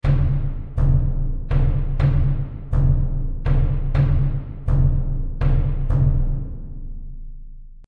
Звуки фанатов на концерте
Барабанный бой, возвещающий начало концерта